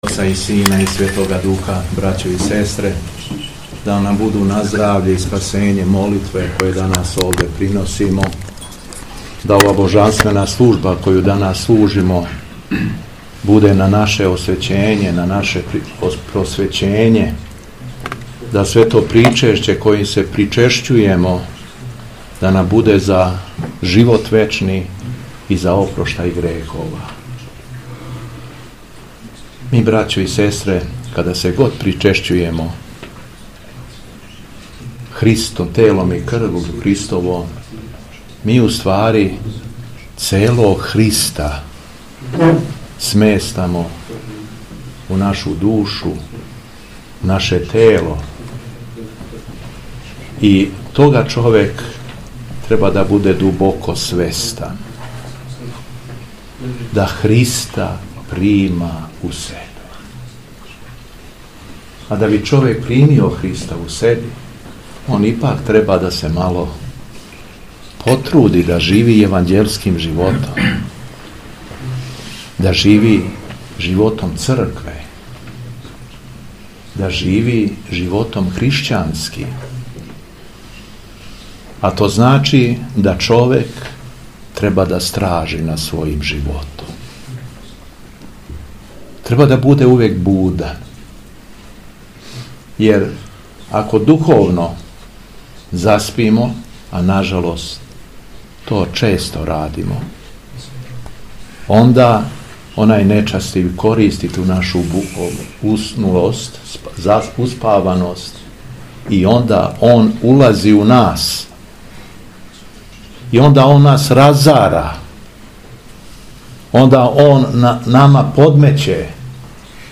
Беседа Његовог Високопреосвештенства Митрополита шумадијског г. Јована
По Светој Литургији Митрополит Јован је све поучио својом беседом: